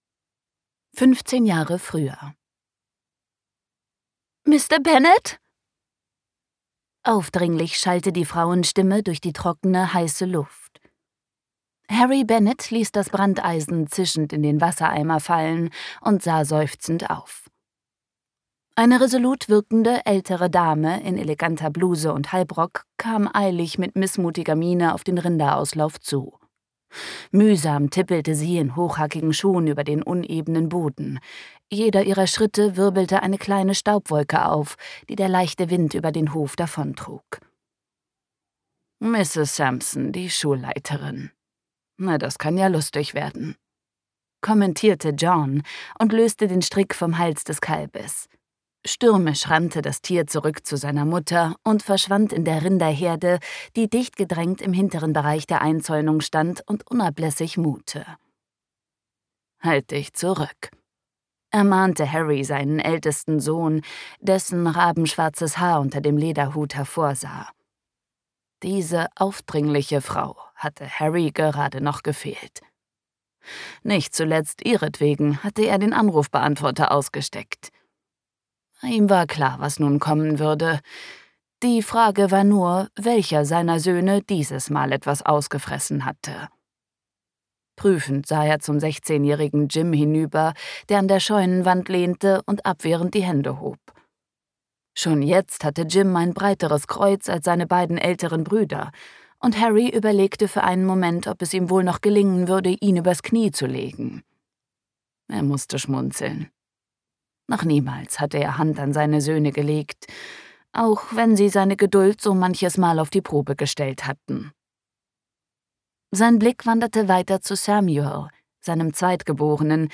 Gekürzt Autorisierte, d.h. von Autor:innen und / oder Verlagen freigegebene, bearbeitete Fassung.